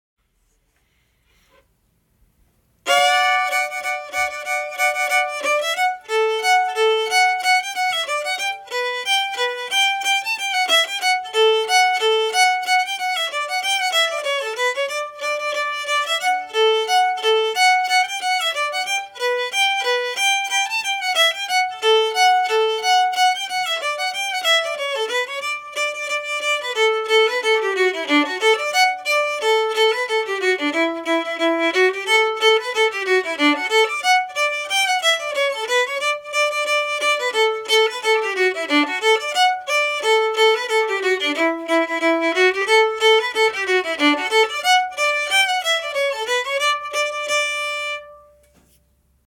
Liberty faster (audio MP3)Download